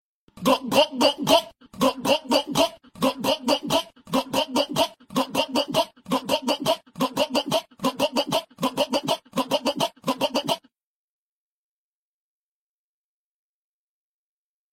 Gulp Gulp Gulp Gulp Sound Effect Free Download
Gulp Gulp Gulp Gulp